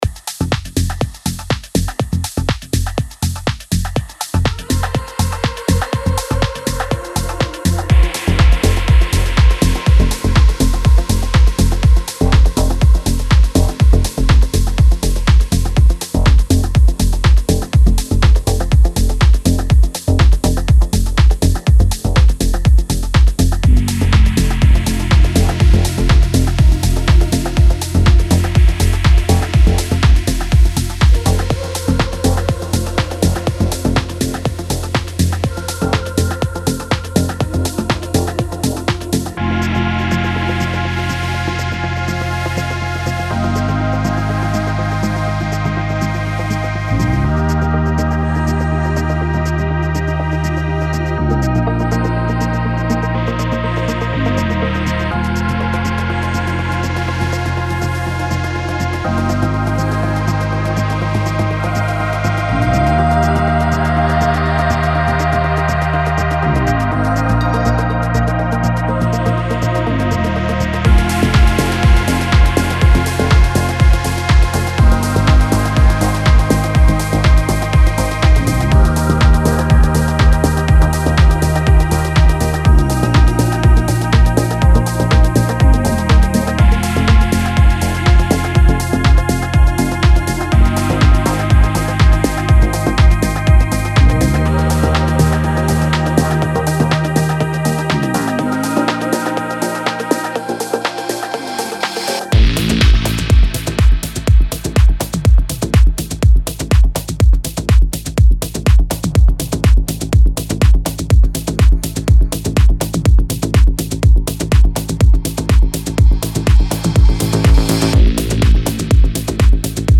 デモサウンドはコチラ↓
Genre:Progressive House
46 Top Drum Loops
36 Bass Loops
26 Synth Loops
16 Vocal Loops
1 Piano Loop